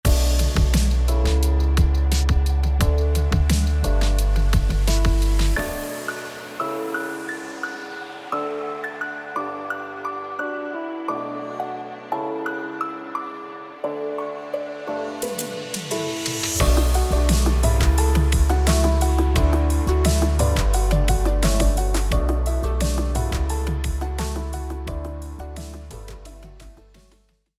ポップス×エレクトロニカ